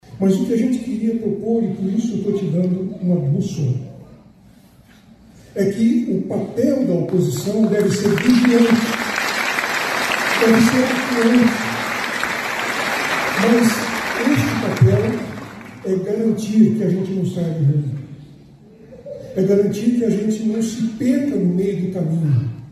A cerimônia de posse dos eleitos em Maringá começou com a instalação da 18º Legislatura.
Os 23 vereadores declararam o voto no microfone.